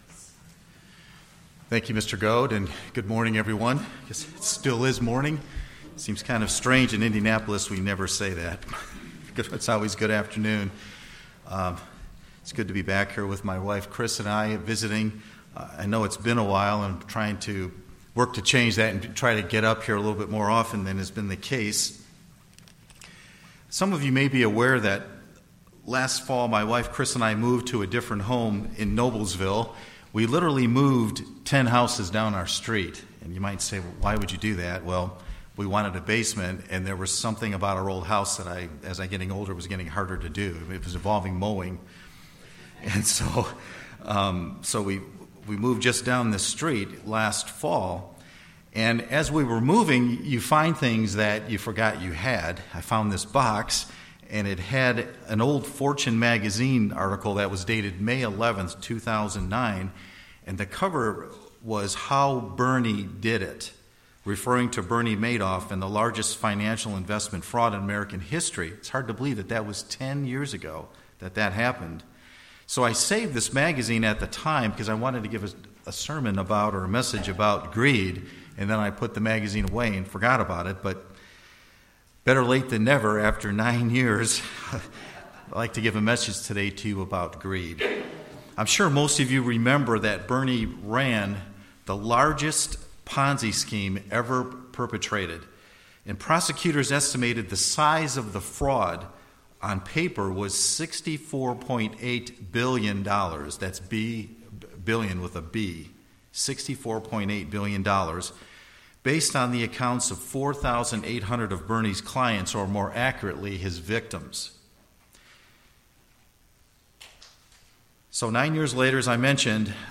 The spiritual sin of greed is very destructive. This sermon takes a look at the Bernie Madoff ponzi scheme and gives us two concepts we can use in order to avoid greed.
Given in Ft. Wayne, IN